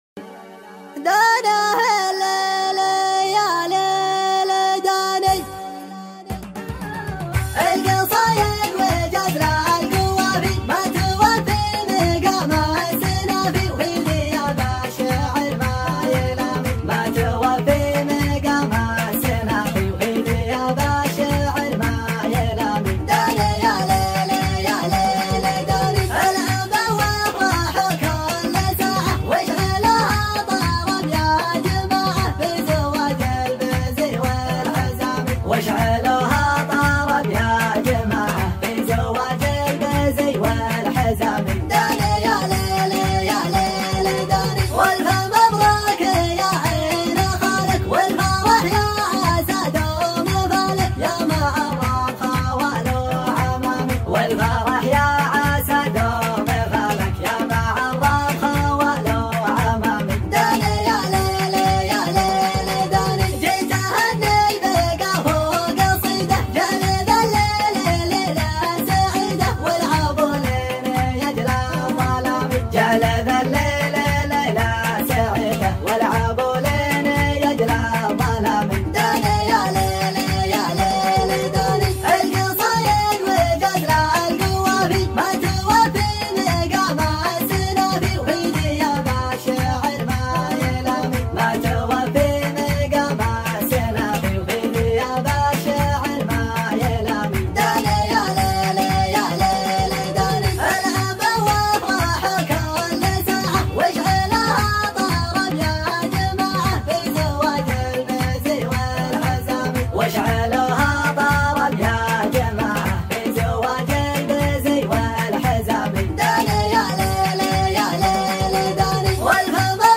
شيلة حمااسية